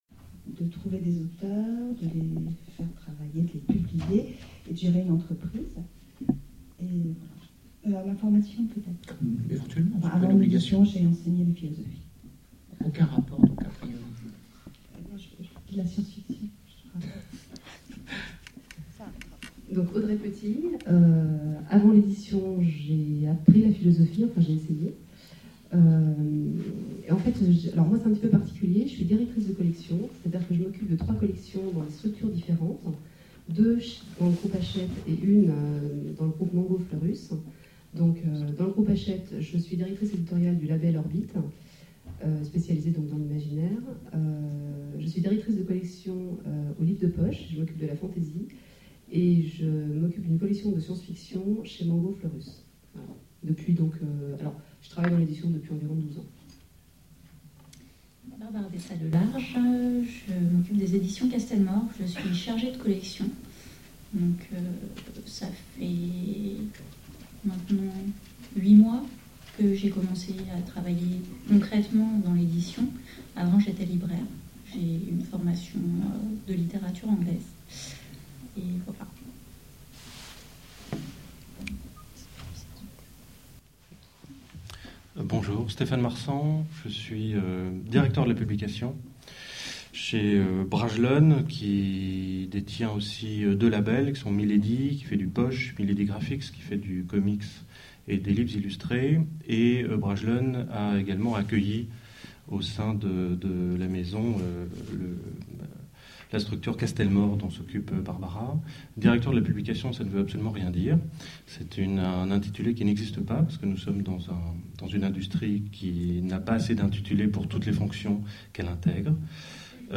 La fantasy, le tour d’un genre : Table ronde d’éditeurs